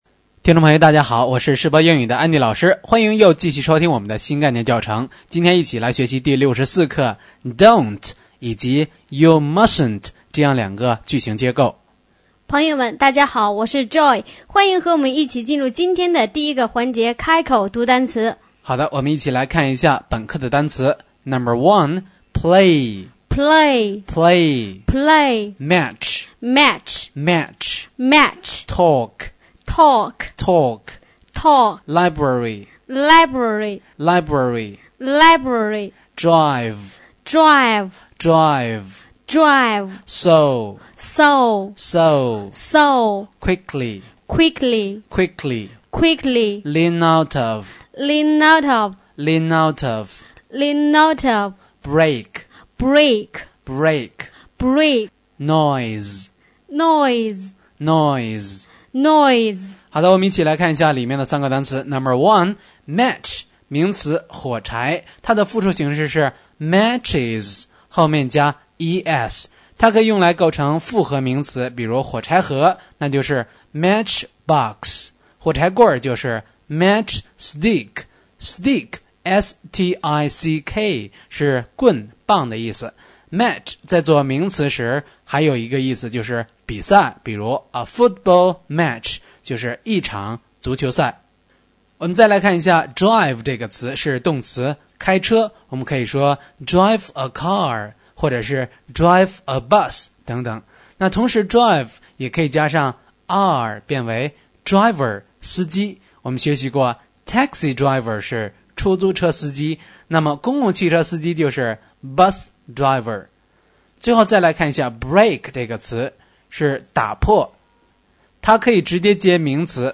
新概念英语第一册第64课【开口读单词】